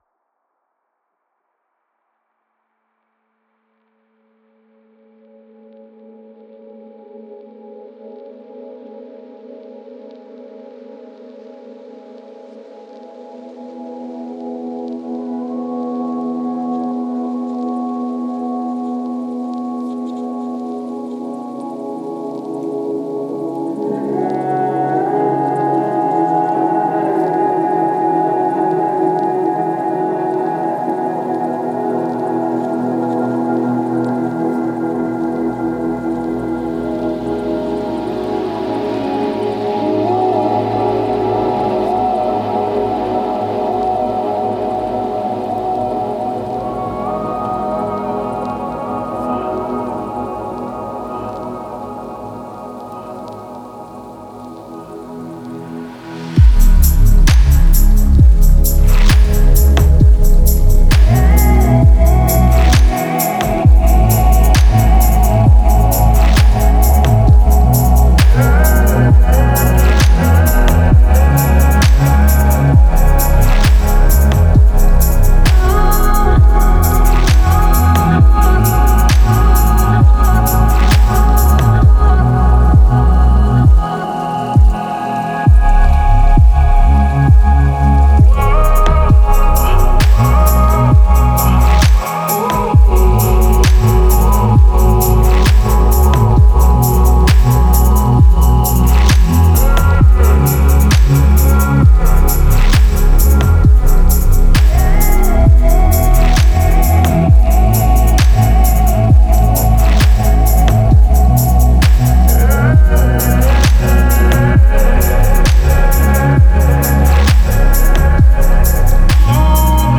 это трек в жанре электронной музыки